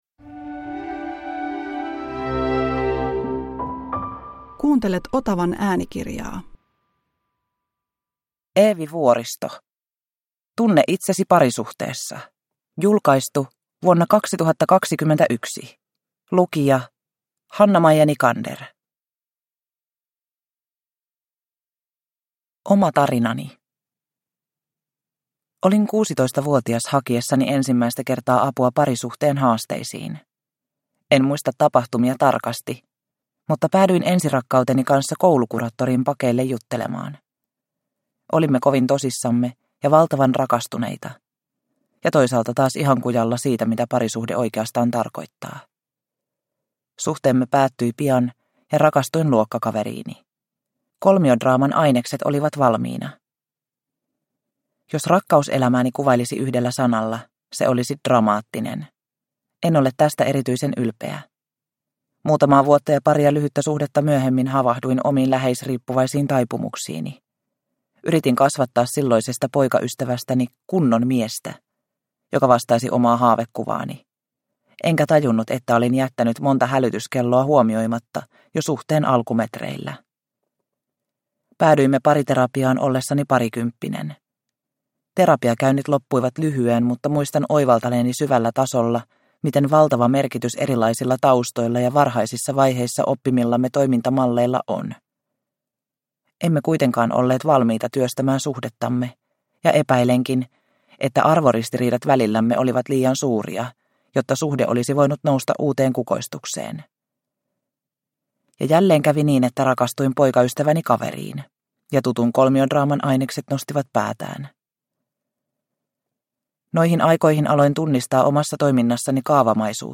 Tunne itsesi parisuhteessa – Ljudbok – Laddas ner